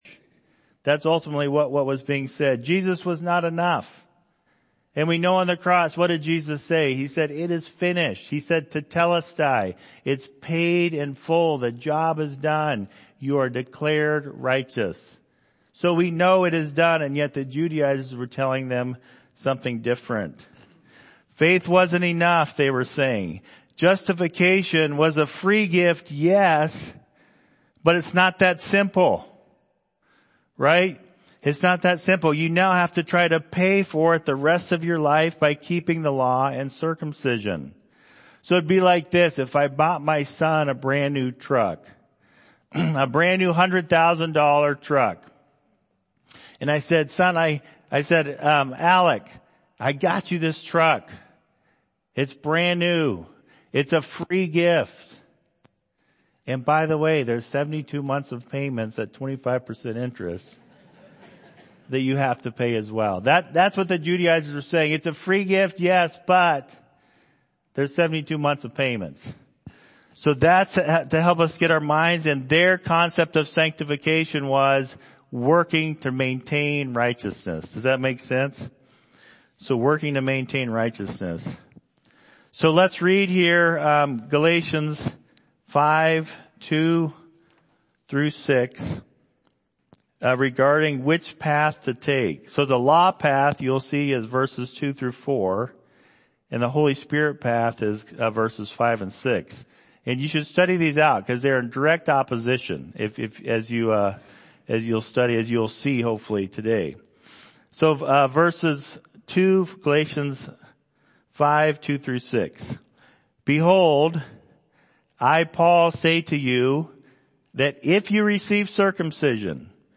Galatians Passage: Galatians 5:2-6 Service Type: Sunday Morning « Staying Where Christ Put Me